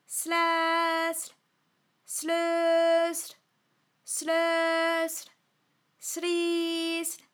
ALYS-DB-001-FRA - First, previously private, UTAU French vocal library of ALYS